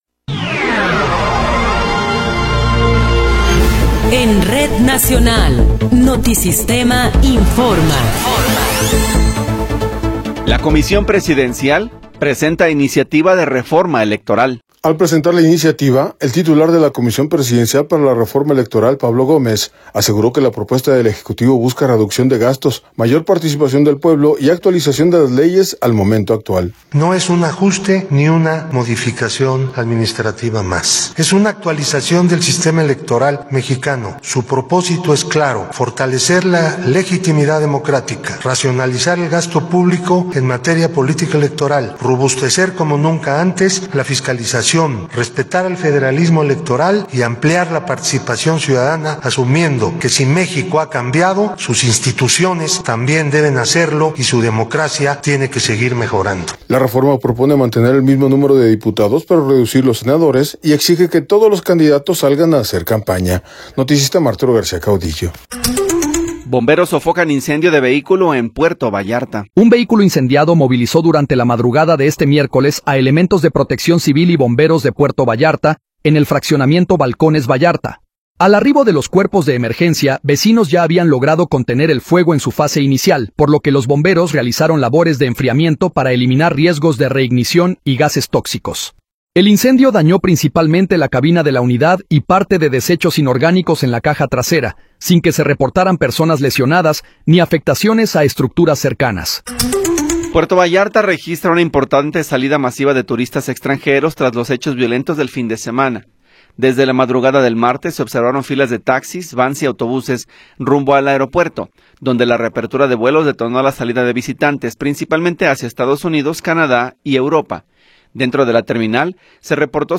Noticiero 10 hrs. – 25 de Febrero de 2026